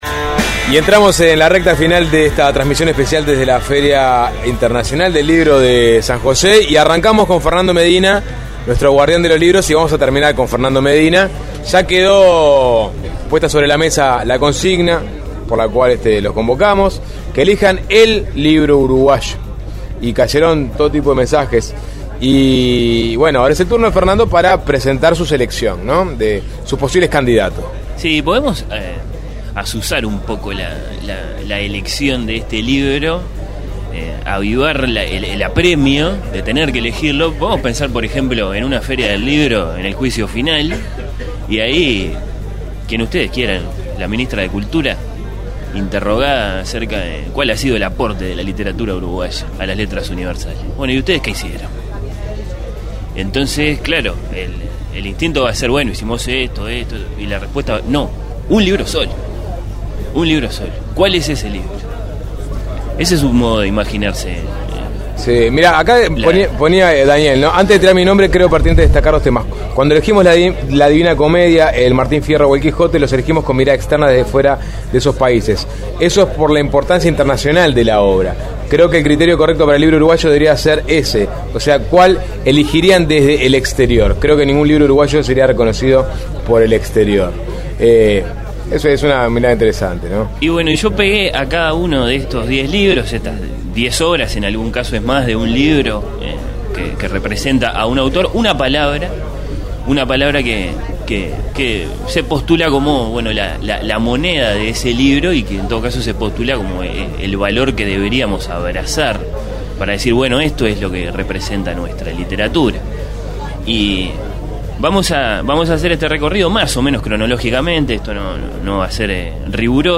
Suena Tremendo ¿Cuál es el libro que mejor representa la literatura uruguaya? Imprimir A- A A+ Desde la Feria del Libro de San José